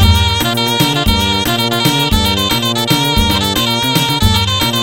zurna-mididemo2.wav